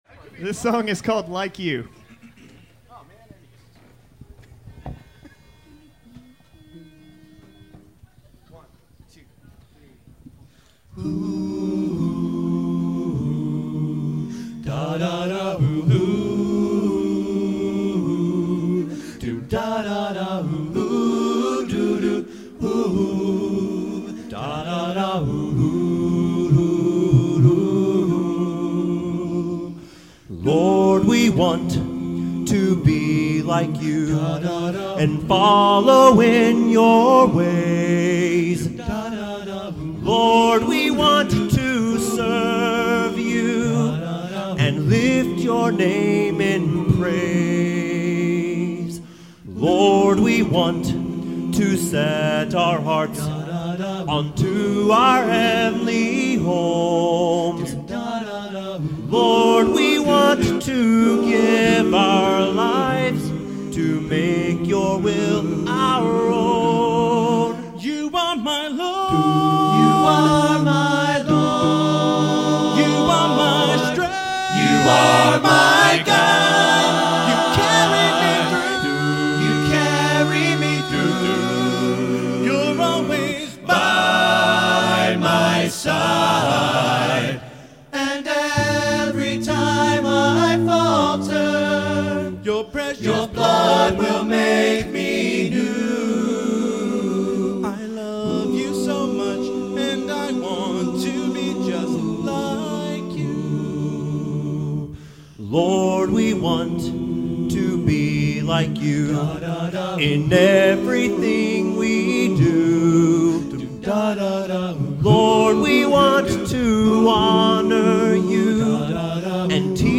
And no Instruments